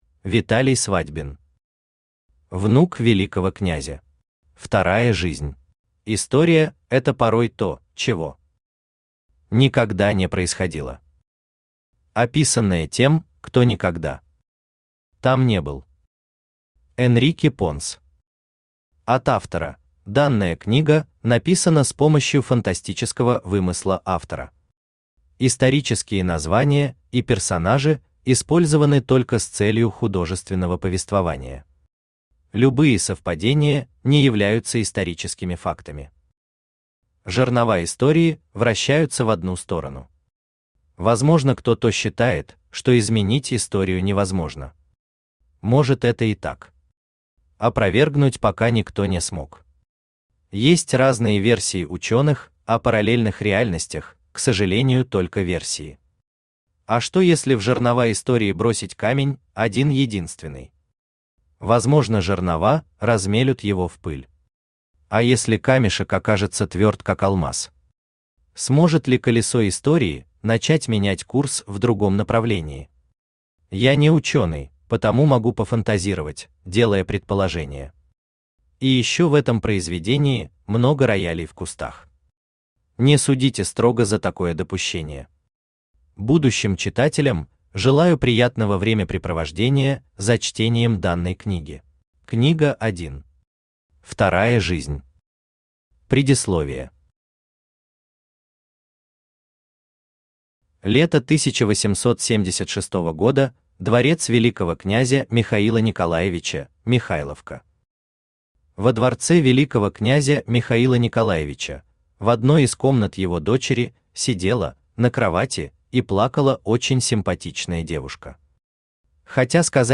Аудиокнига Внук Великого князя. Вторая жизнь | Библиотека аудиокниг
Aудиокнига Внук Великого князя. Вторая жизнь Автор Виталий Свадьбин Читает аудиокнигу Авточтец ЛитРес.